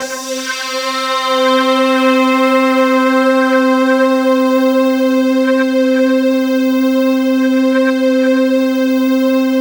Index of /90_sSampleCDs/Infinite Sound - Ambient Atmospheres/Partition C/07-RANDOMPAD